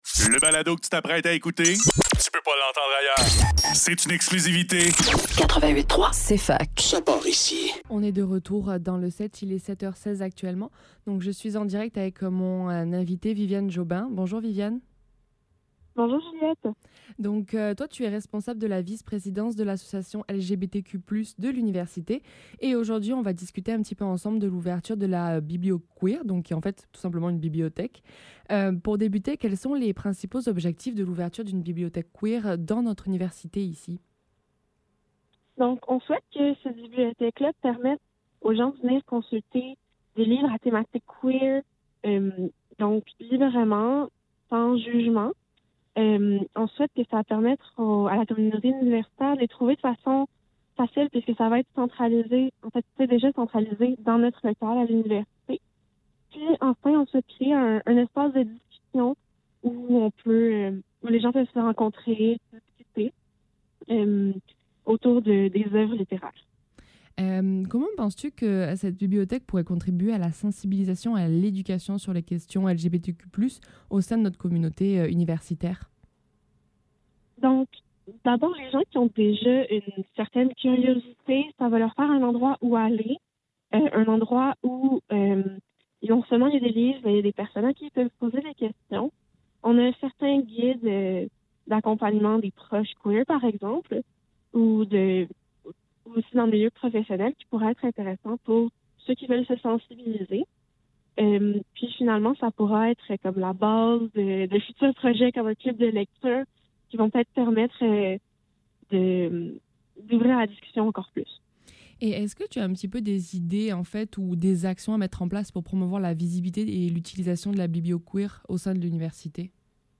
Le SEPT - Entrevue